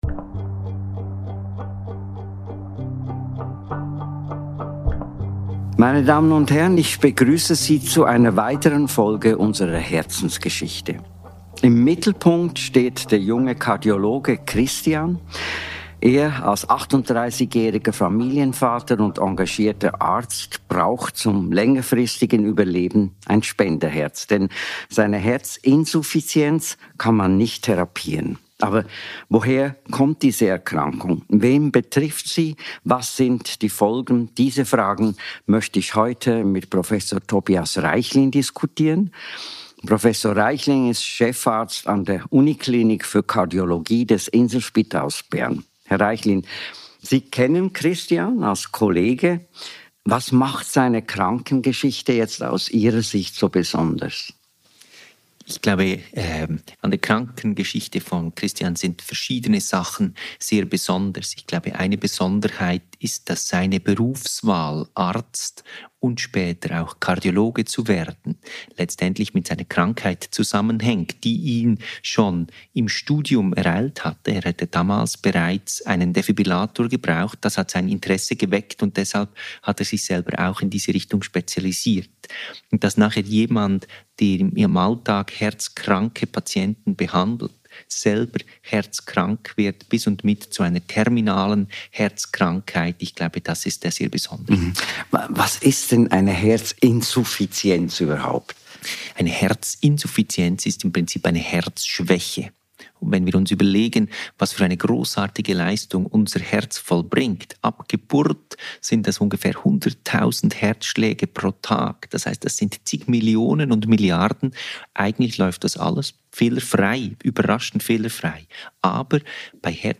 Moderator Kurt Aeschbacher